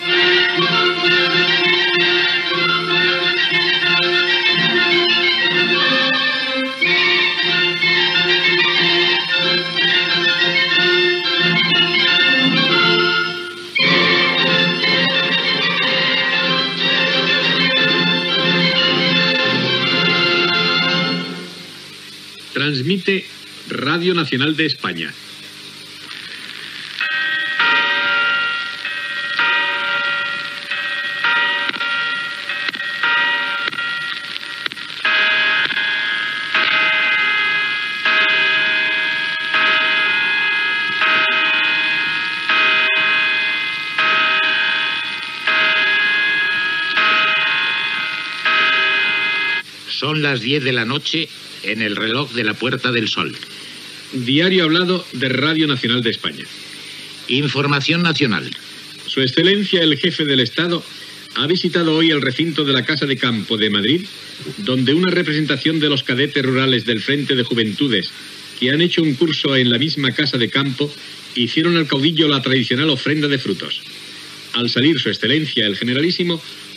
Sintonia, identificació de l'emissora, campanades del rellotge de la Puerta del Sol de Madrid (22 hores), hora i identificació del programa, el "generalísimo" Franco ha visitat la Casa de Campo de Madrid.
Informatiu
Recreació feta, cap a l'any 1977, amb motiu dels 40 anys de Radio Nacional de España, per dos locutors d'aquella època.